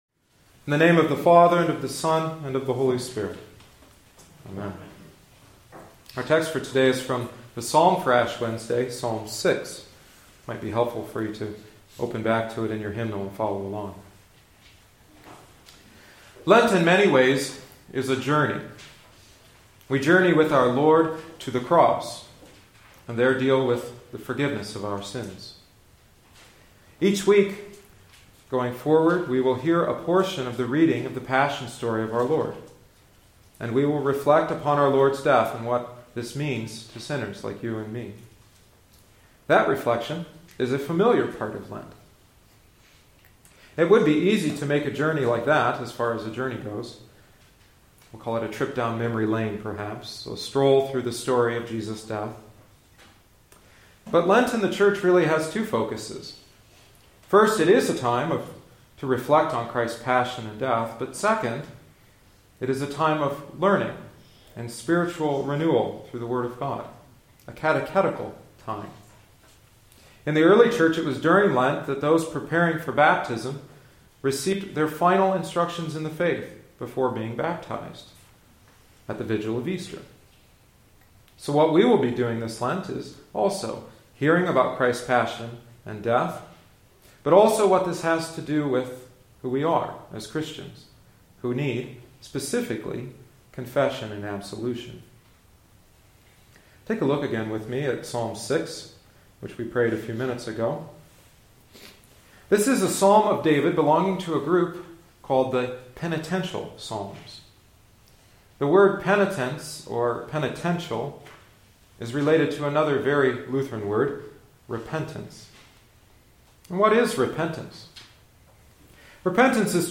Audio Only. in Sermons | 13 February, 2013 | 26 Words | Comment